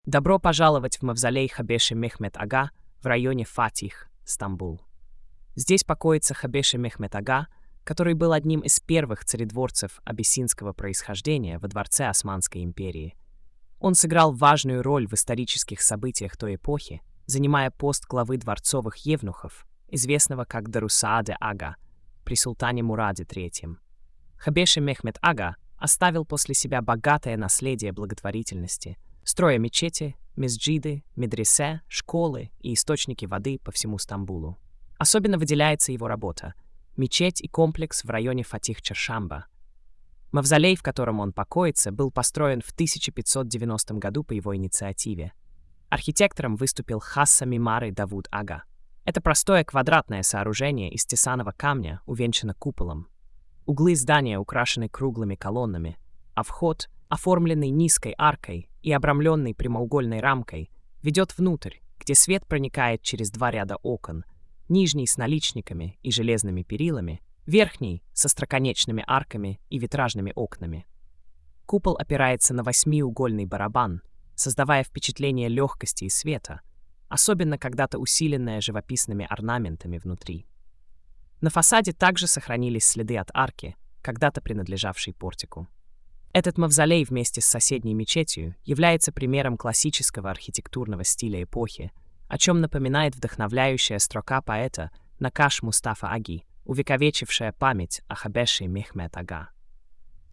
Аудиоповествование: